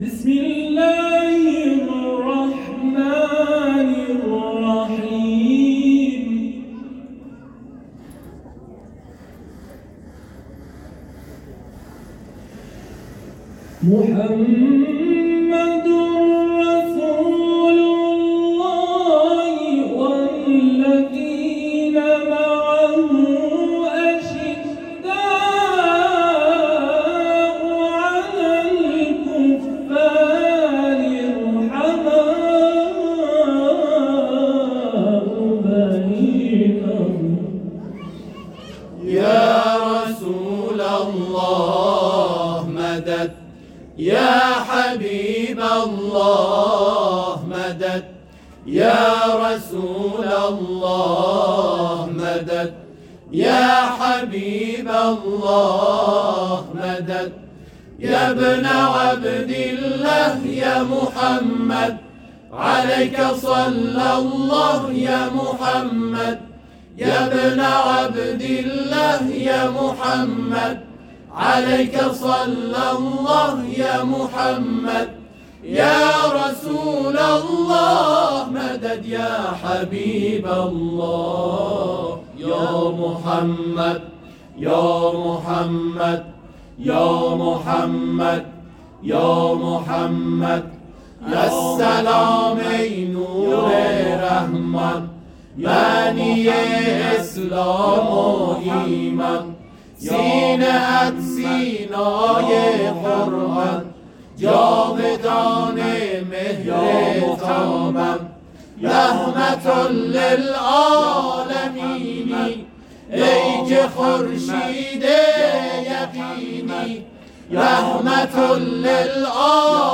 گروه همخوانی و تواشیح بصائر شهرستان بروجن، روز گذشته، ۱۱ مهرماه همزمان با میلاد سراسر نور خاتم‌الانبیا حضرت محمد مصطفی(ص) و حضرت امام صادق(ع) به اجرای مدیحه‌سرایی پیامبر نور و رحمت پرداختند؛ این مدیحه در سه نقطه شامل امامزاده میر حیدرشاه(ع) نقنه، مسجدالنبی(ص) و مهدیه شهر بروجن اجرا شد.
برچسب ها: گروه تواشیج ، شهرستان بروجن ، گروه همخوانی ، میلاد پیامبر(ص)